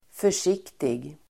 Uttal: [för_s'ik:tig]